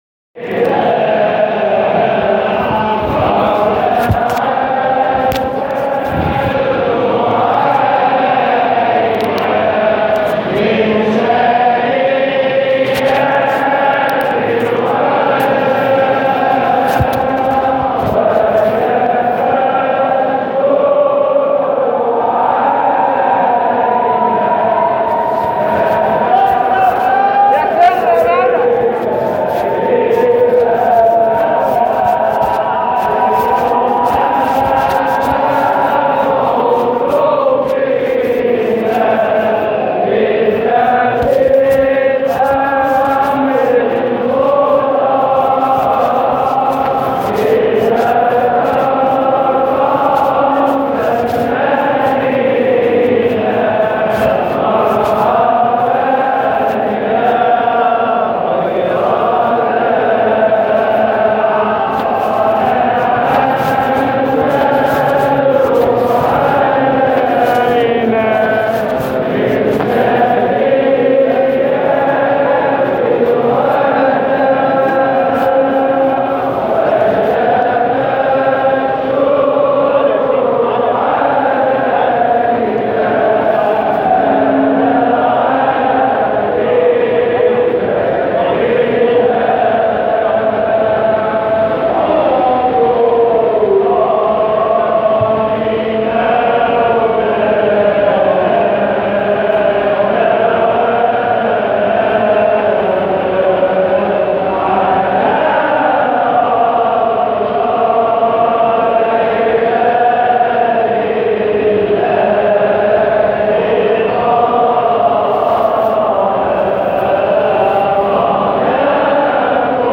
مقاطع من احتفالات ابناء الطريقة الحامدية الشاذلية بمناسباتهم